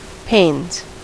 Ääntäminen
Ääntäminen US Haettu sana löytyi näillä lähdekielillä: englanti Pains on sanan pain monikko.